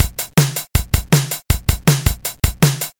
プリセットの「electronic-coffee」をROM2でベタ打ちしたリズムパターンですが、『ピンクブルー』のドラムサウンドにかなり近いです。
この手の【これぞ80年代のドラムの音】を再現するのにピッタリ。